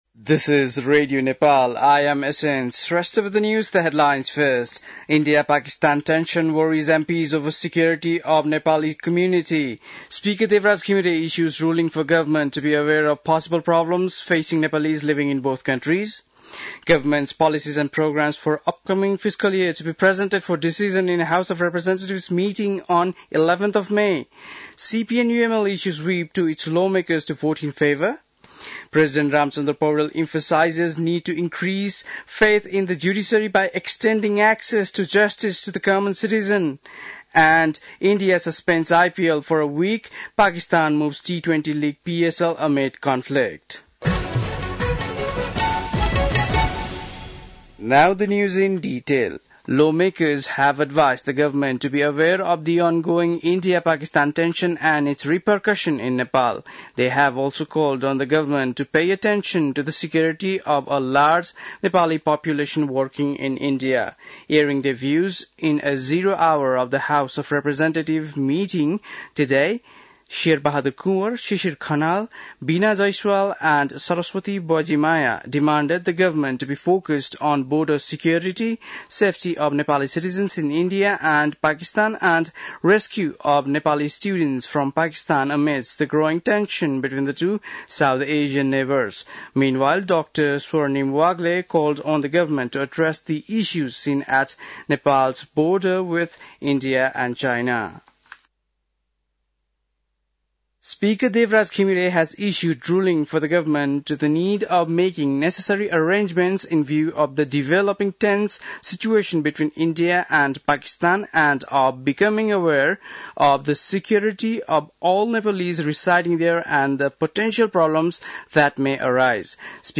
बेलुकी ८ बजेको अङ्ग्रेजी समाचार : २६ वैशाख , २०८२
8-pm-english-news-1-27.mp3